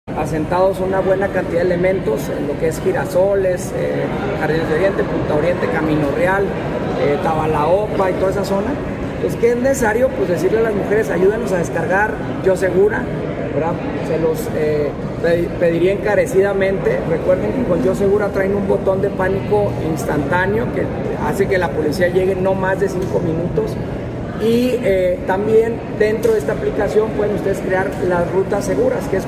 AUDIO: MARCO ANTONIO BONILLA MENDOZA, PRESIDENTE MUNICIPAL DE CHIHUAHUA